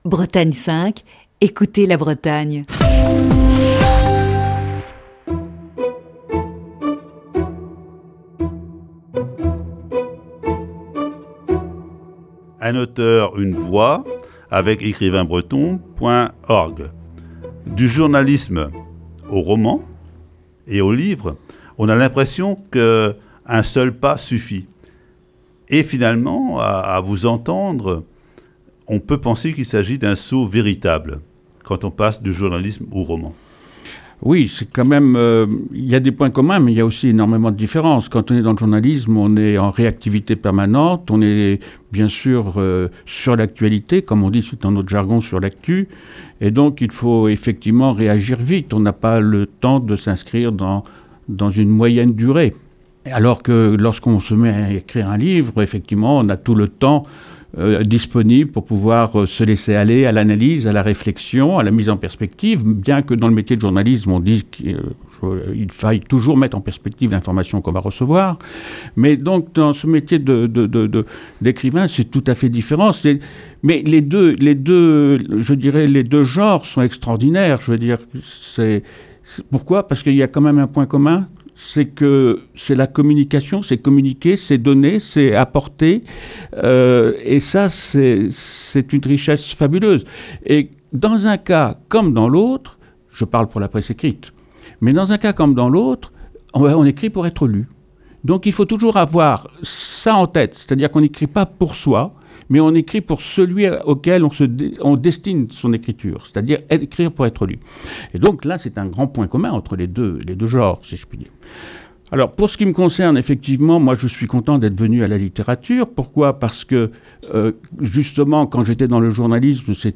Chronique du 28 avril 2020.